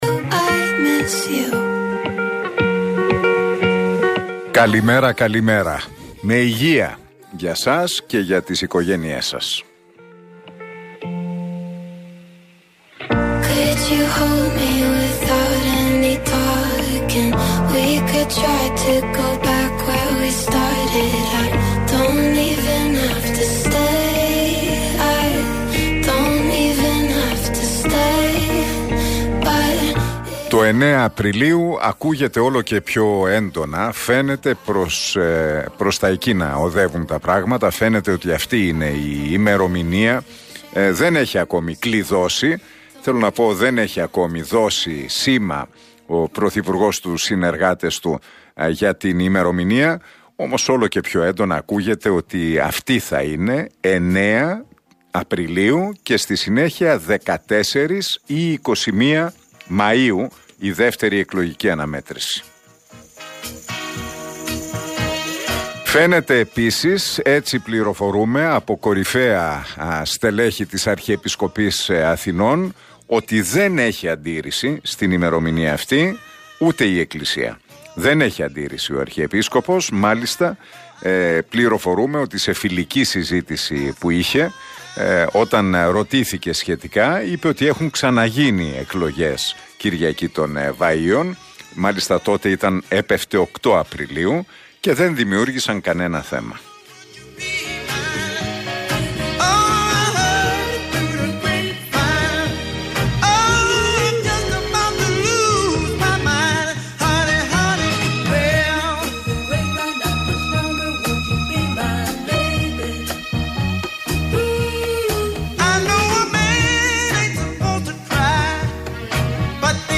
Σύμφωνα με τις πληροφορίες που μετέδωσε ο Νίκος Χατζηνικολάου στην εκπομπή του στον Realfm 97,8, οι ημερομηνίες που φαίνεται να προκρίνονται είναι η 9η Απριλίου για τις πρώτες εκλογές και στη συνέχεια η 14η ή 21η Μαΐου για τις δεύτερες εκλογές.